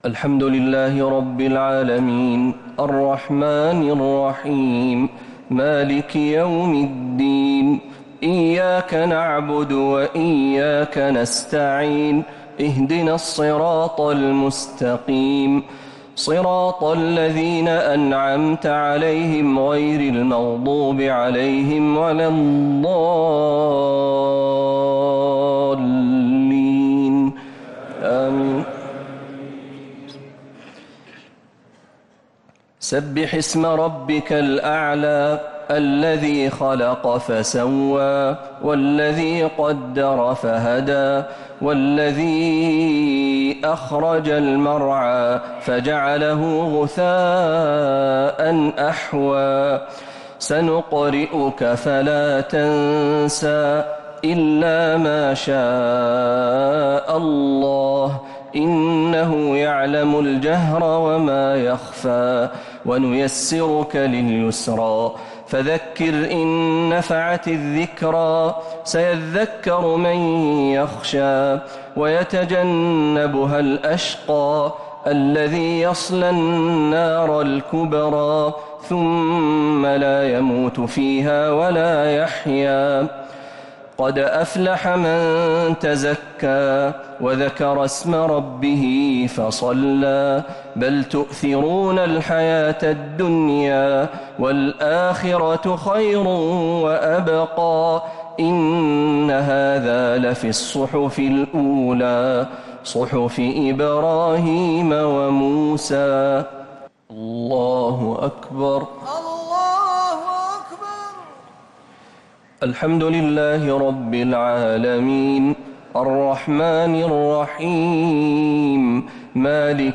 الشفع و الوتر ليلة 9 رمضان 1447هـ | Witr 9th night Ramadan 1447H > تراويح الحرم النبوي عام 1447 🕌 > التراويح - تلاوات الحرمين